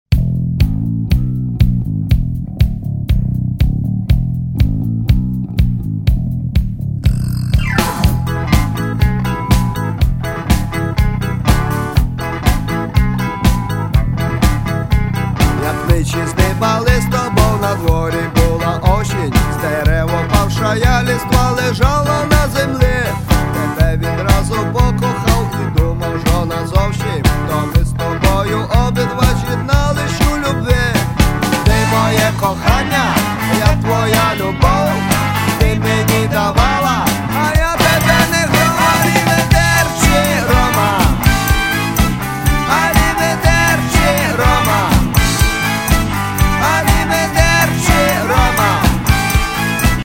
Home » CDs» Rock My account  |  Shopping Cart  |  Checkout